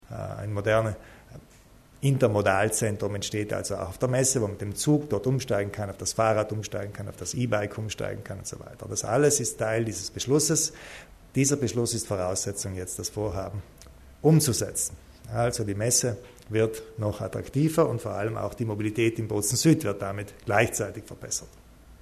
Landeshauptmann Kompatscher erläutert das Projekt zur Steigerung der Attraktivität der Messe Bozen